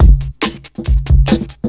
ELECTRO 03-L.wav